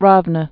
(rôvnə)